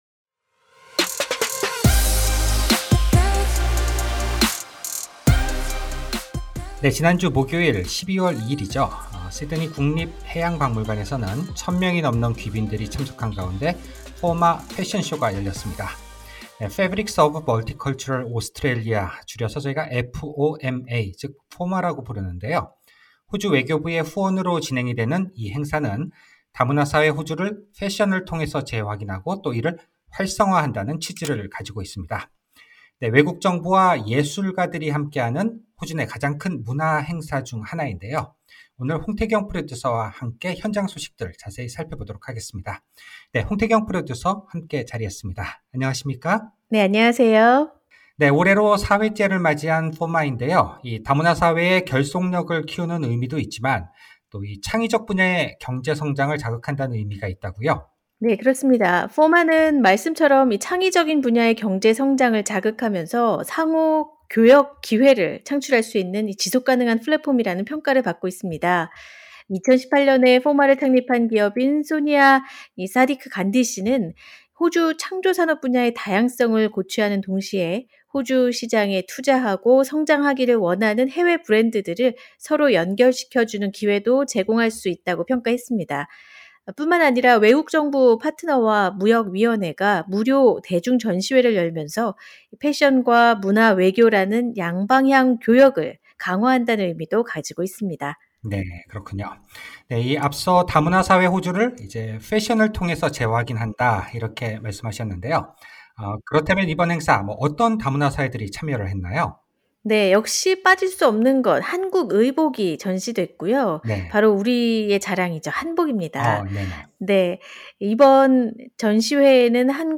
foma_2021_cross_talk.mp3